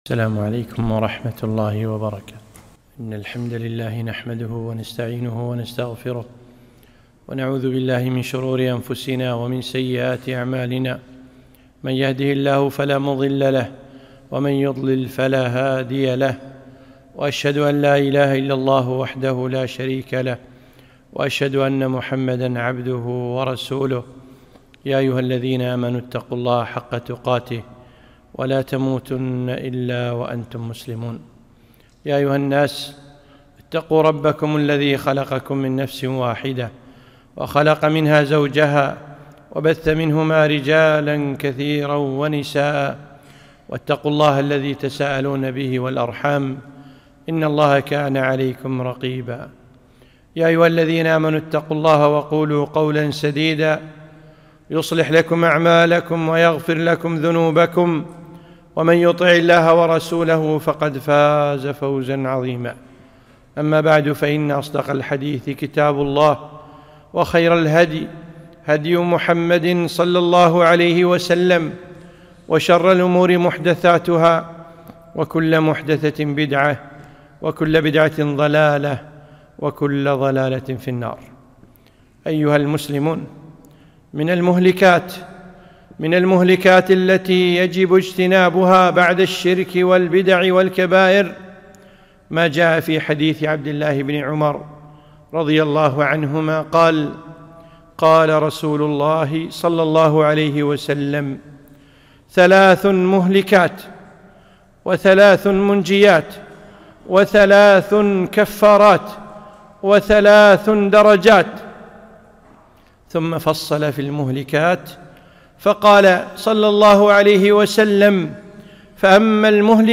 خطبة - من المهلكات